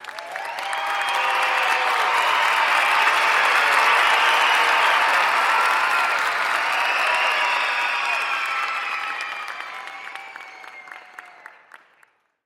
Звуки аплодисментов, оваций
Аплодисменты на футбольном стадионе